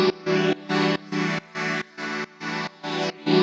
Index of /musicradar/sidechained-samples/140bpm